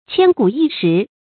千古一時 注音： ㄑㄧㄢ ㄍㄨˇ ㄧ ㄕㄧˊ 讀音讀法： 意思解釋： 喻指難得的機會。